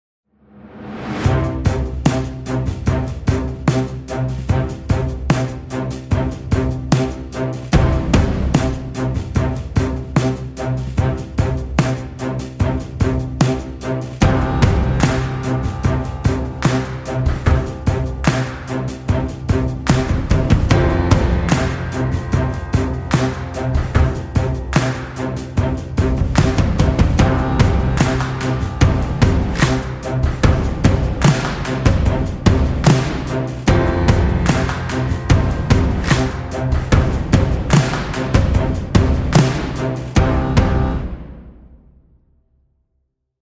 This library was recorded in a variety of spaces — three recording studios, a living room, hallways, foyers, a dining room, bedroom, and a 7500 sq ft warehouse.
816 individual one-shot and multiple velocity samples, 29 bangs and hits, 11 booms, 70 claps in big + small spaces, 40 studio and living room kick in 6 variations, 186 warehouse kicks in 4 variations, 13 reverses, 23 rims + clicks, 24 slaps, 41 snaps in big + small spaces, 264 snares in 4 variations, 45 stomps in big + small spaces, 18 swells, 40 toms in 3 variations, 12 toys
nearly 400 loops in multiple variations, tempos ranging from 72 – 124 bpms, variety of grooves and feels